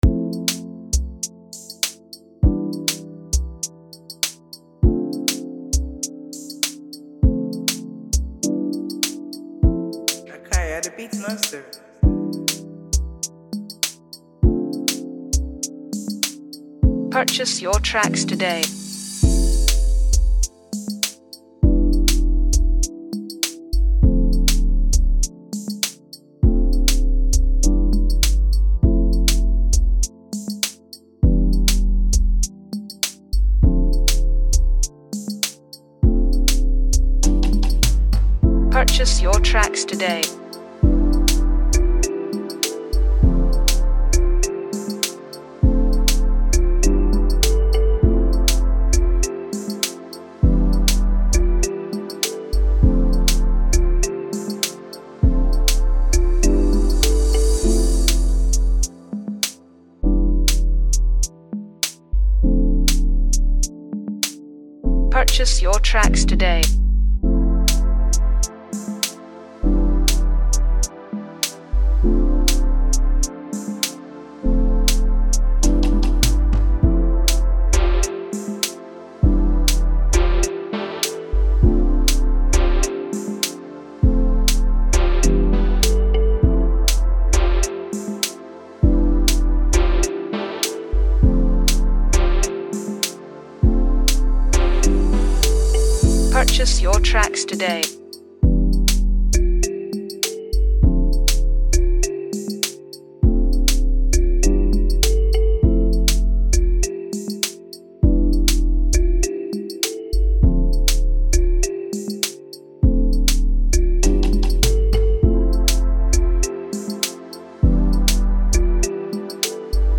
inspired type of beat